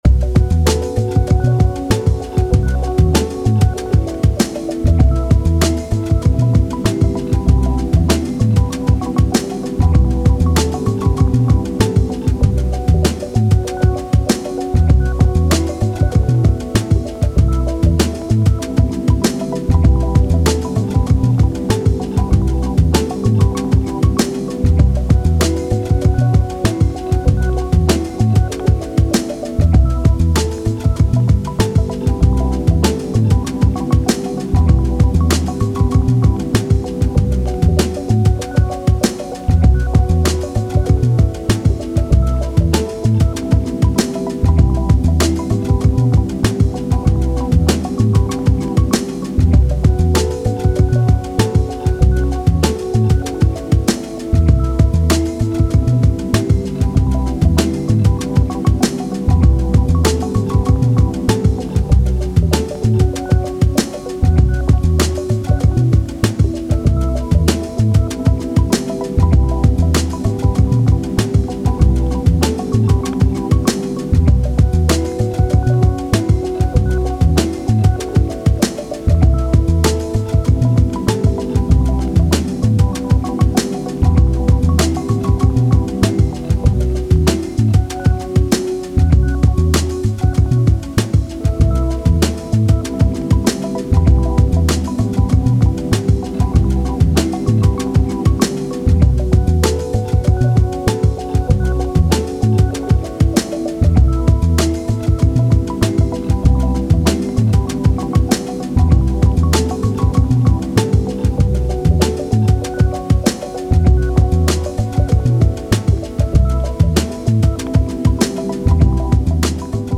Sharing my first beat using Absynth 6. I used 4 instances: One patch I made from scratch, another patch was a preset I did mutate and a third patch I resampled and played backwards, plus the bass is also from Absynth 6.